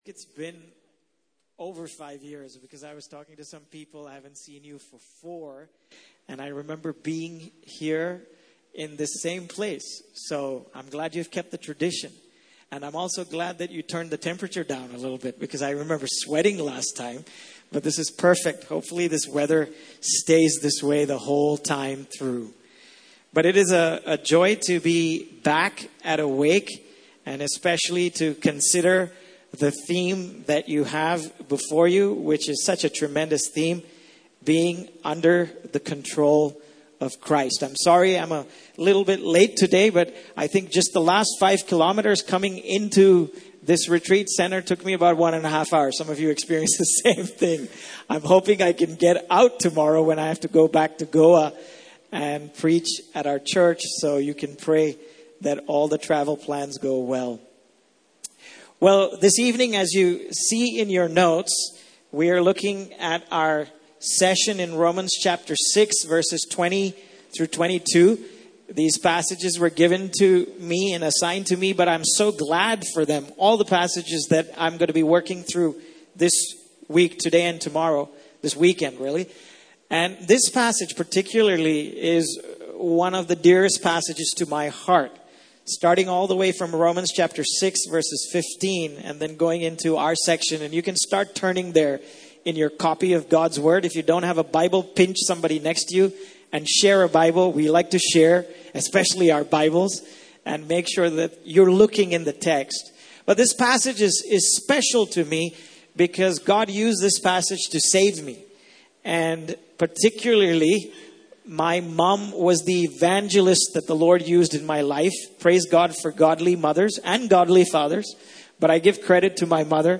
Passage: Romans 6:20-22 Service Type: Main Session